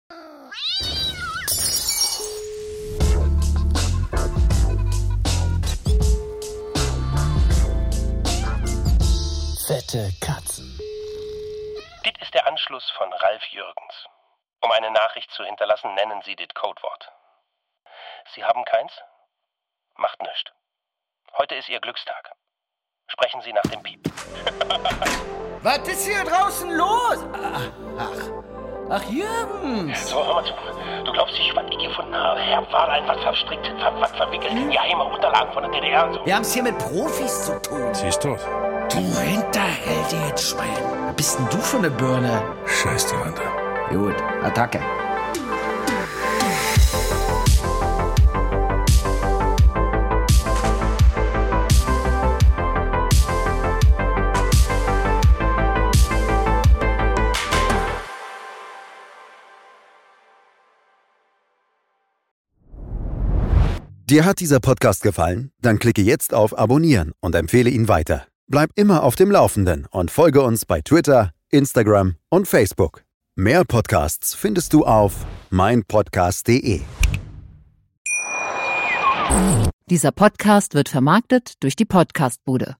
Ralf Jürgens - Ronald Zehrfeld
Sunny Lettmann - Ludwig Trepte